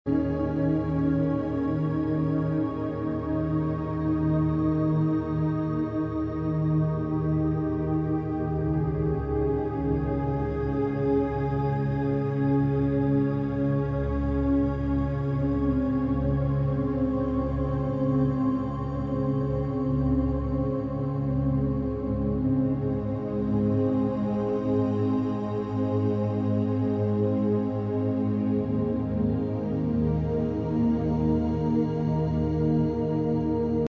Fréquence de guérison 🧘🏼